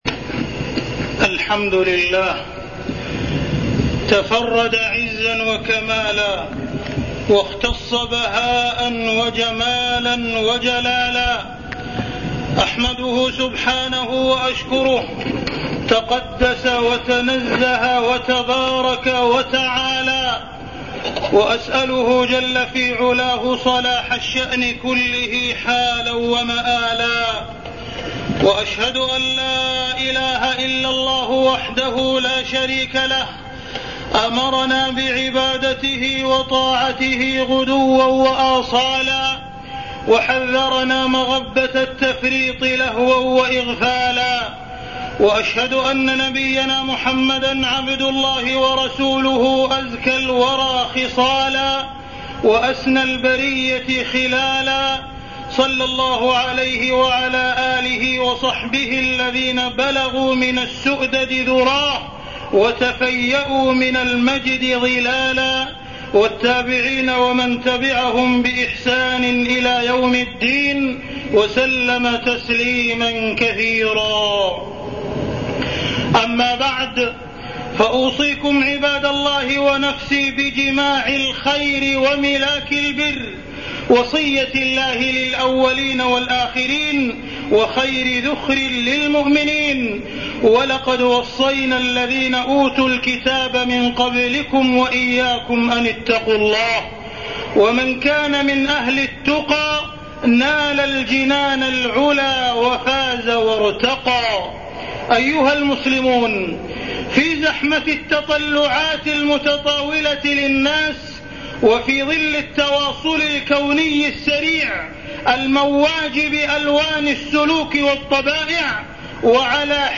تاريخ النشر ١١ جمادى الأولى ١٤٢٤ هـ المكان: المسجد الحرام الشيخ: معالي الشيخ أ.د. عبدالرحمن بن عبدالعزيز السديس معالي الشيخ أ.د. عبدالرحمن بن عبدالعزيز السديس الاجازة الصيفية